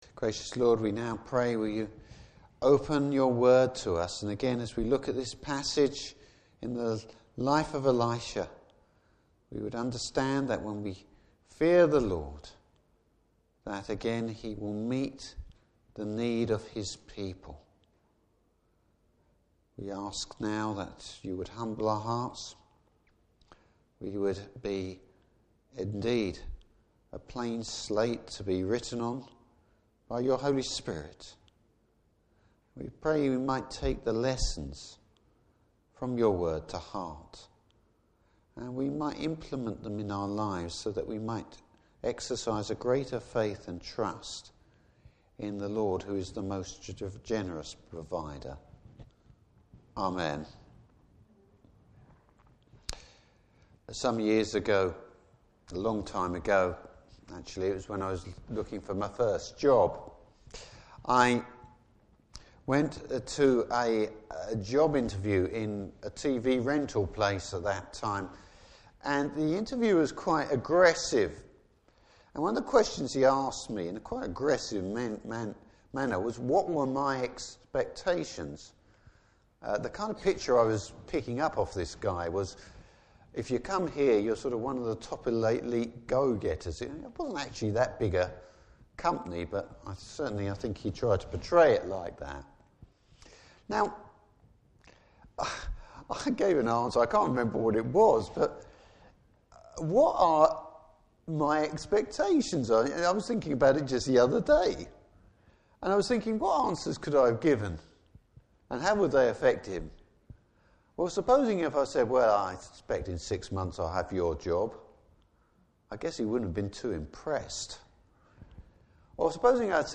Service Type: Evening Service Bible Text: 2 Kings 4:1-7.